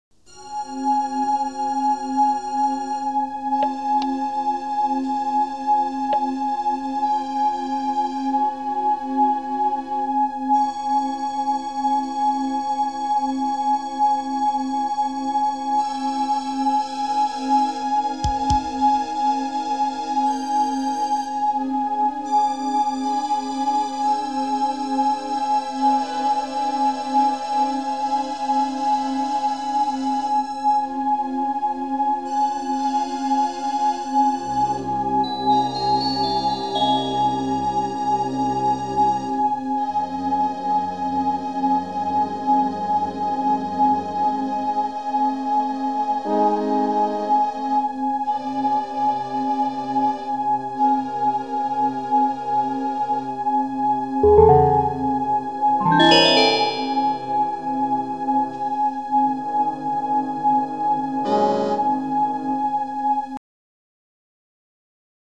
Daraus ergibt sich eine rhythmische Struktur eines 4/4 Taktes, die als horizontales Raster über die gesamten Fassaden gelegt wird.
Dies ergibt eine temperierte Rasterung von 4 Oktaven und zwei Halbtönen.